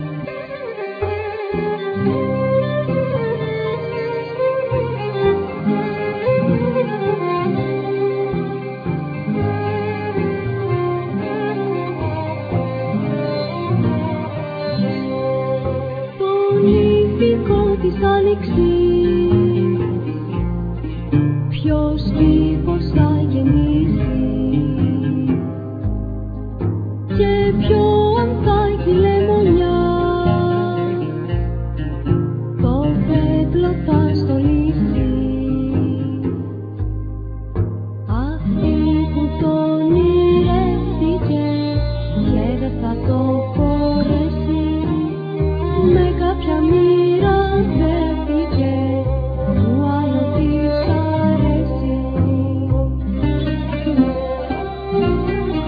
Nylon string guitar,Mandokino,Bass
Vocals
Lyra
Piano,Keyboards
Drums,Percussions